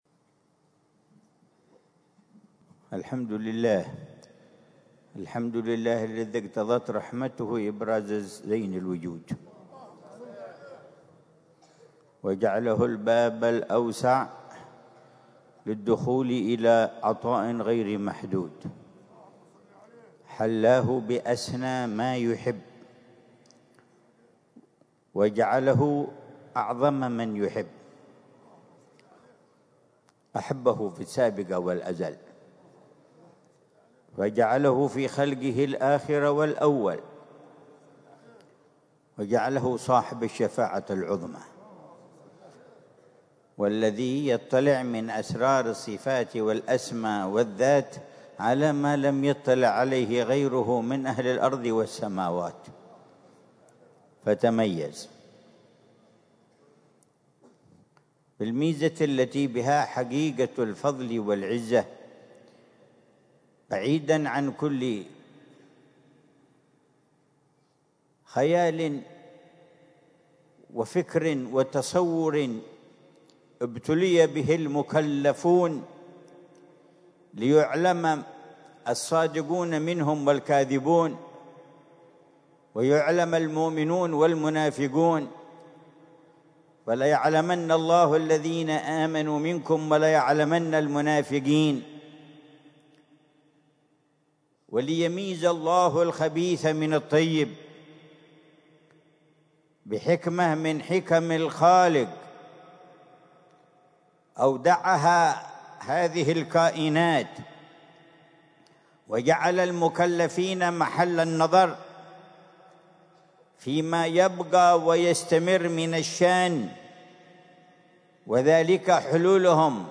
محاضرة العلامة الحبيب عمر بن محمد بن حفيظ ضمن سلسلة إرشادات السلوك ليلة الجمعة 23 جمادى الأولى 1447هـ في دار المصطفى، بعنوان: